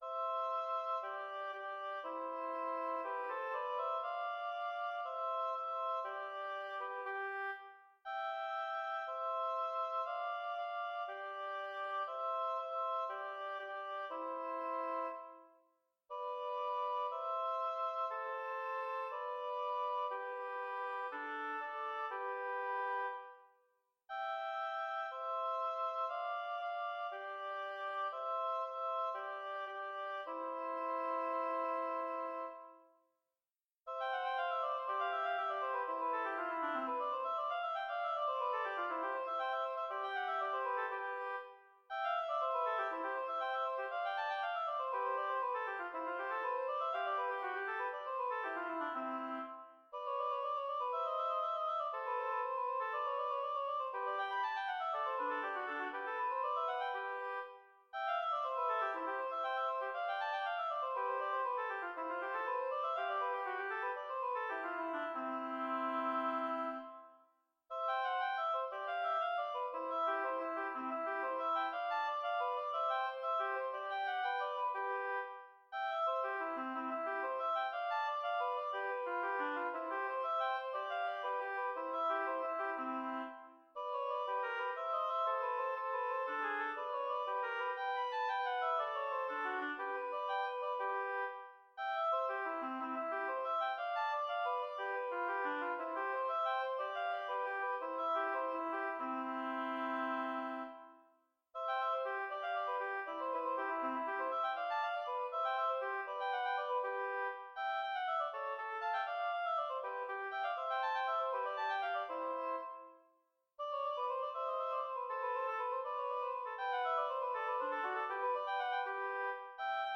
Intermediate oboe duet